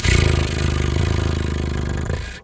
cat_2_purr_05.wav